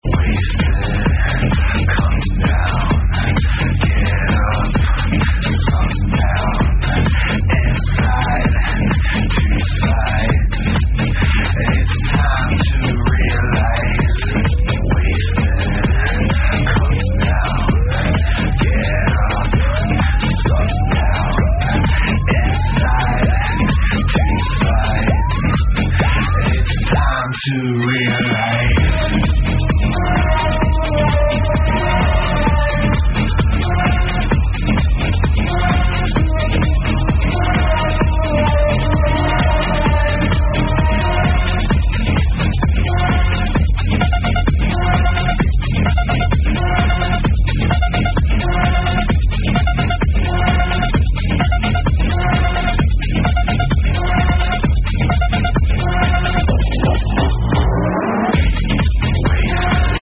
Live @ Dance Valley